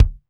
Index of /90_sSampleCDs/Northstar - Drumscapes Roland/DRM_Pop_Country/KIK_P_C Kicks x